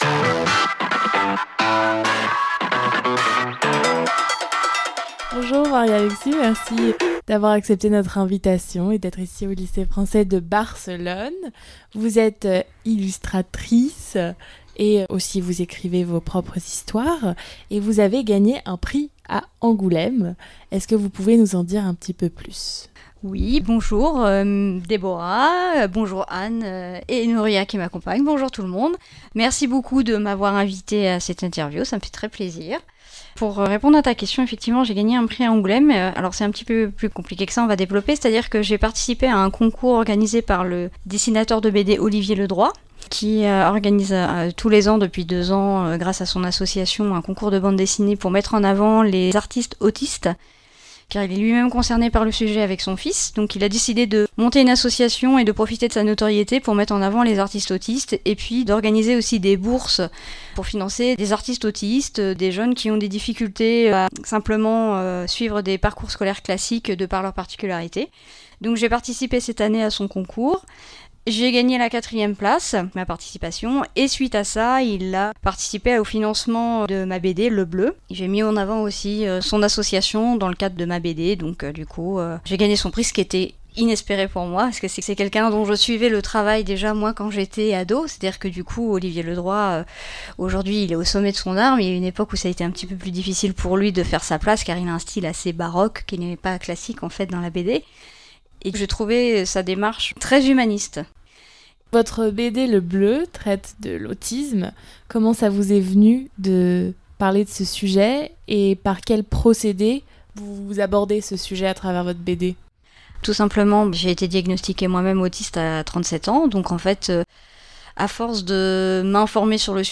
Conférence - Débat - Littérature - Culture = Une invitée au micro de la Web Radio. À l'occasion del "Salón dels Cómics" tenu à Barcelone